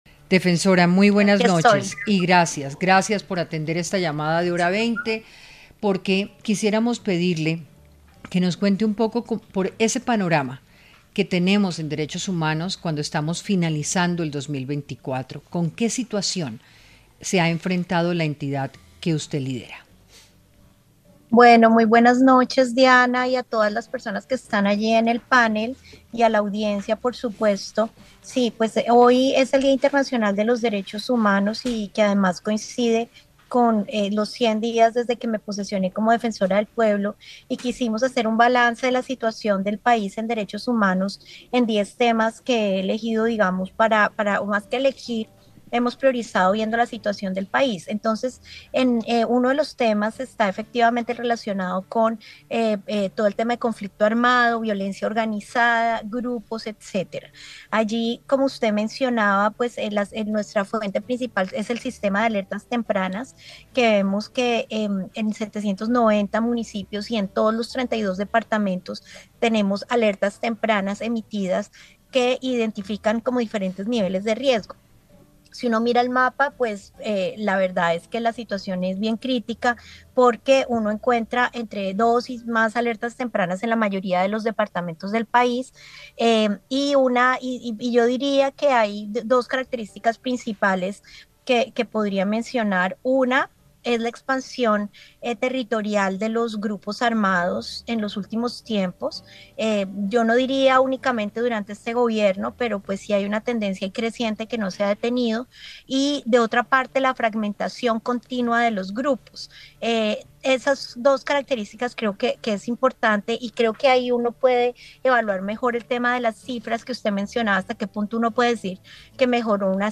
En diálogo con Hora20 de Caracol Radio, la defensora del pueblo, Iris Marín, planteó que en el marco del Día Internacional de los Derechos Humanos y de los primeros 100 días de su gestión, se quiso hacer un balance en derechos humanos, ente el cual, uno de los temas es conflicto armado y violencia organizada.